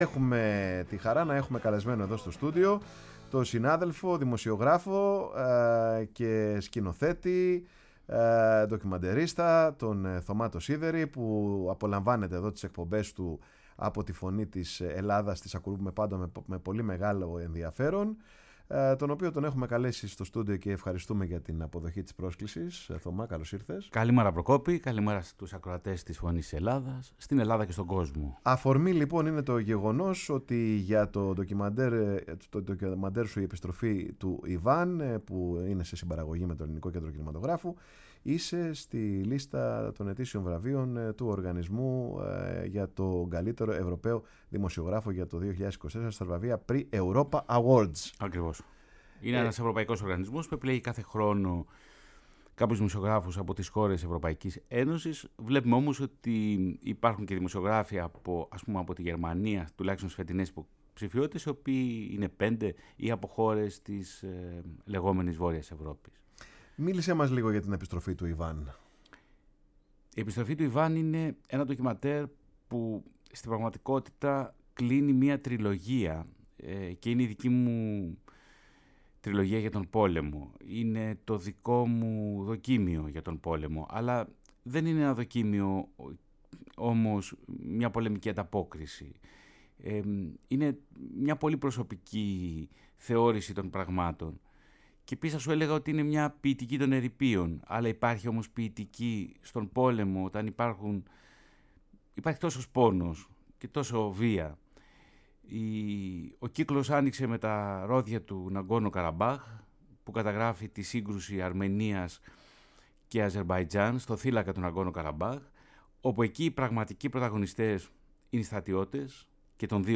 φιλοξένησε σήμερα στο στούντιο η εκπομπή